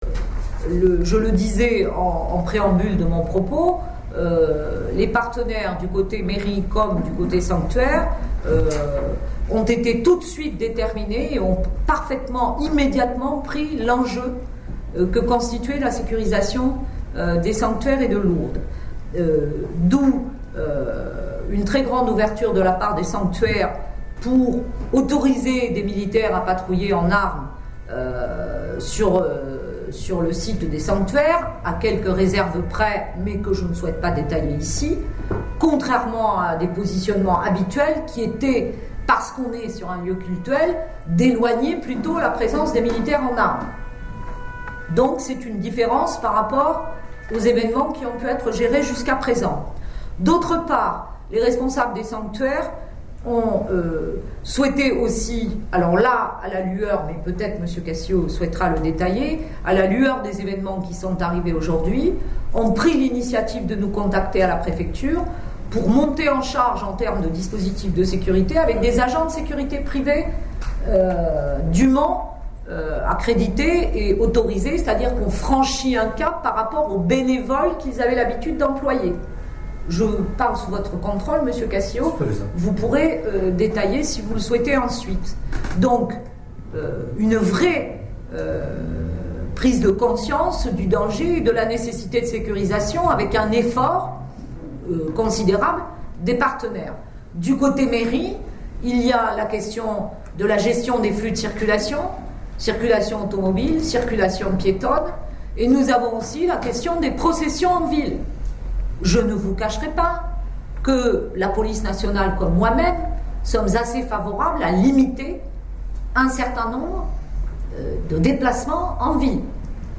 préfète des Hautes-Pyrénées a tenu une conférence de presse en mairie de Lourdes